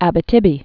(ăbĭ-tĭbē), Lake